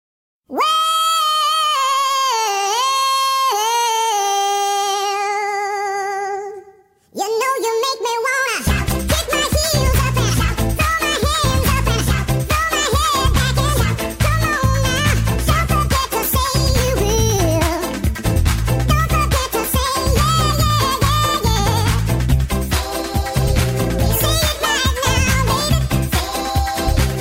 Kategória: Vicces